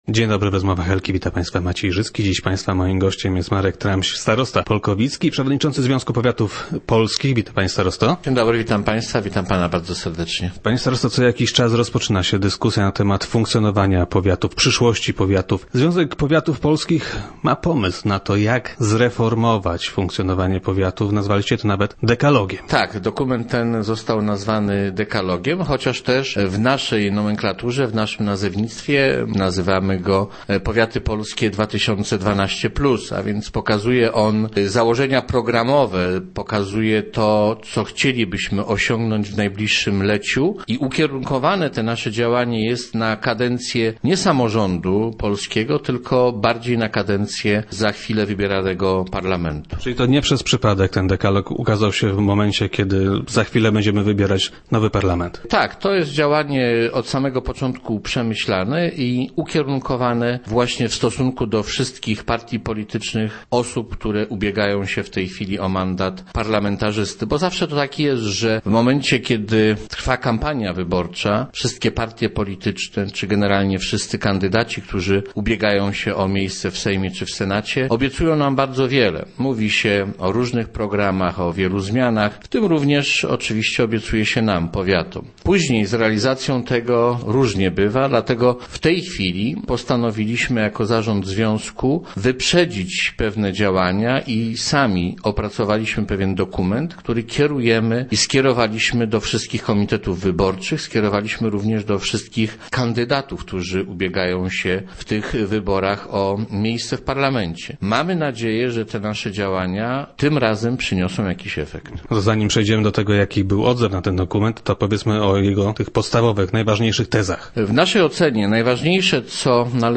0801_trams.jpgZmiany w finansowaniu powiatów i starostowie wybierani w bezpośrednich wyborach. To tylko niektóre tezy dekalogu ogłoszonego przez Związek Powiatów Polskich. Gościem wtorkowych Rozmów Elki był Marek Tramś, starosta polkowicki i przewodniczący Związku Powiatów.